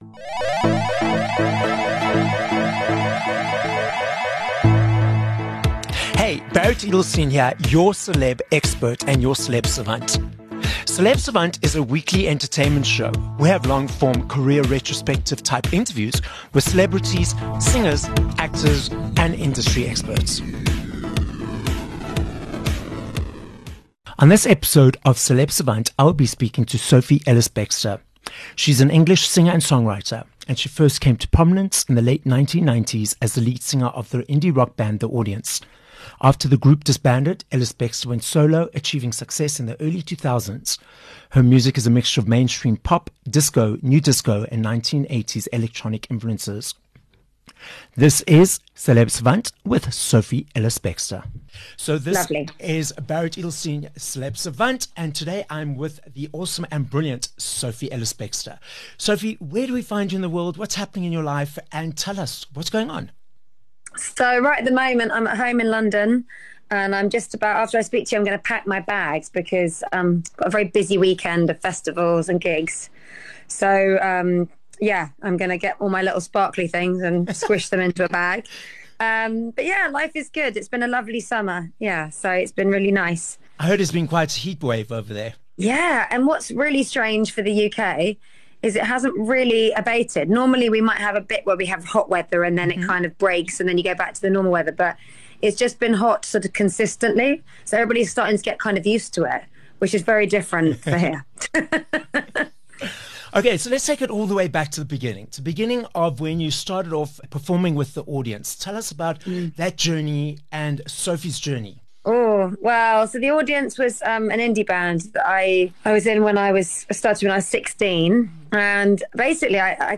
6 Sep Interview with Sophie Ellis-Bextor